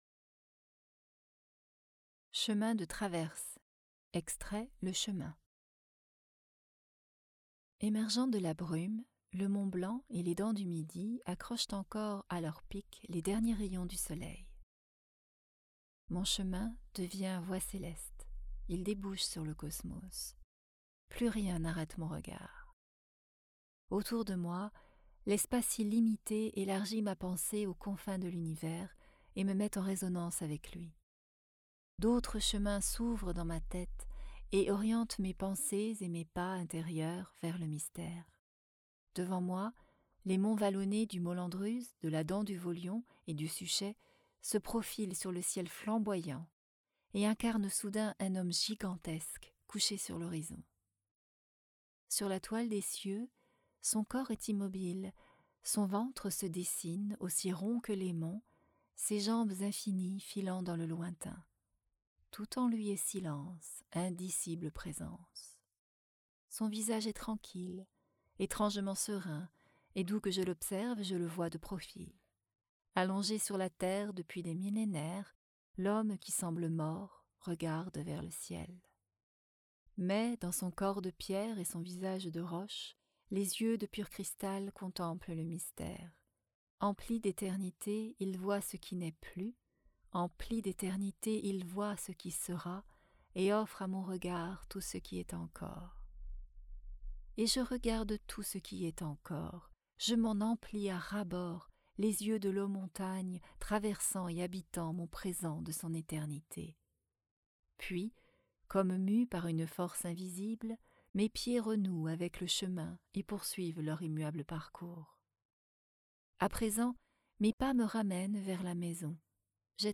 Extraits voix off.